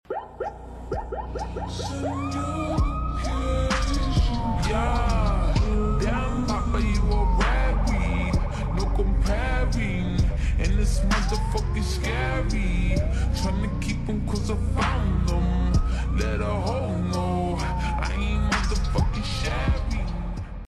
Streets With Police Sirens Sound Effects Free Download